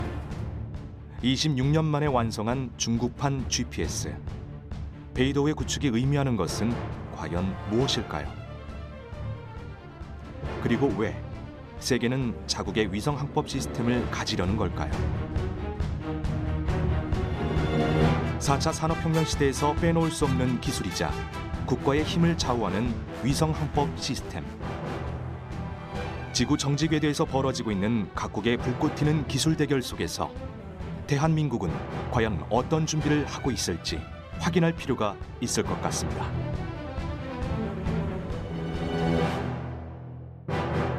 新闻播报【活力男声】